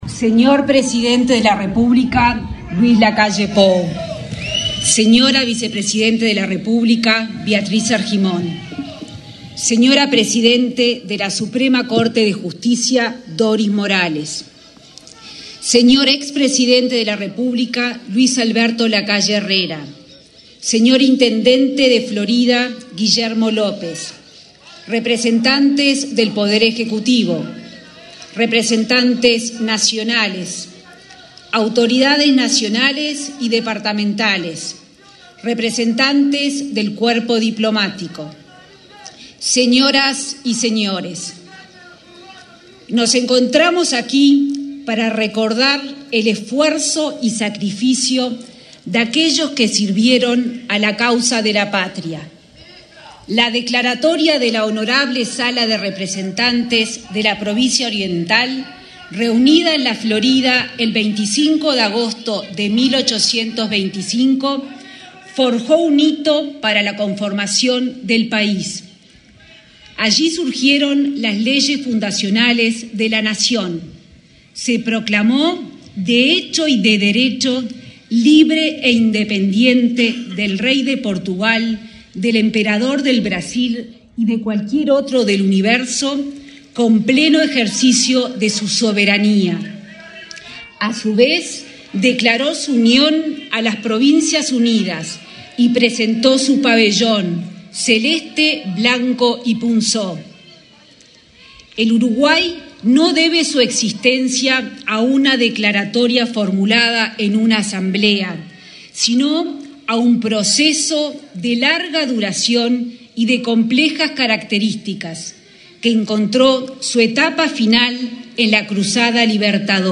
Palabras de la ministra de Economía y Finanzas, Azucena Arbeleche
Palabras de la ministra de Economía y Finanzas, Azucena Arbeleche 25/08/2023 Compartir Facebook X Copiar enlace WhatsApp LinkedIn Con la presencia del presidente de la República, Luis Lacalle Pou, se realizó, este 25 de agosto, el acto conmemorativo del 198.° aniversario de la Declaratoria de la Independencia. La ministra de Economía y Finanzas, Azucena Arbeleche, realizó la oratoria.